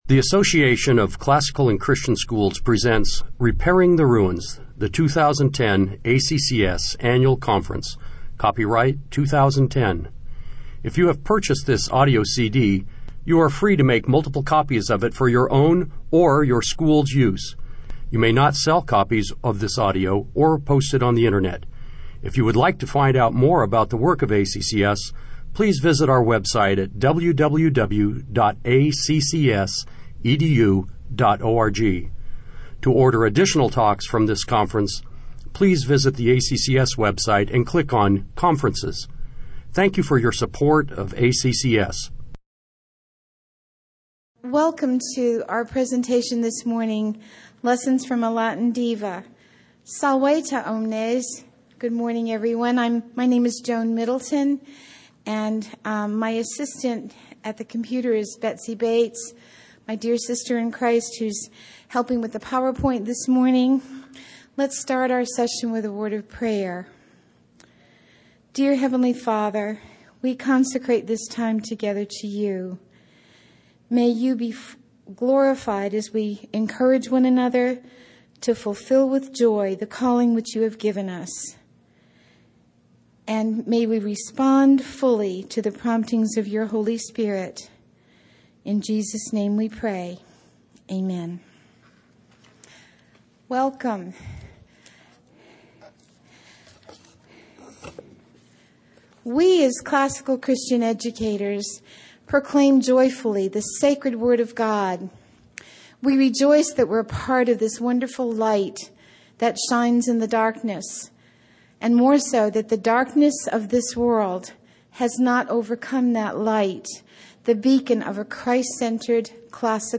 2010 Workshop Talk | 1:04:15 | 7-12, Latin, Greek & Language
The Association of Classical & Christian Schools presents Repairing the Ruins, the ACCS annual conference, copyright ACCS.